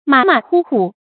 馬馬虎虎 注音： ㄇㄚˇ ㄇㄚˇ ㄏㄨˇ ㄏㄨˇ 讀音讀法： 意思解釋： 形容做事不認真；不仔細。